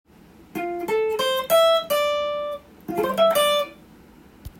Dm7のコード上で使えるフレーズをtab譜にしてみました。
２～３本ほどの弦をさらっと弾くフレーズになります。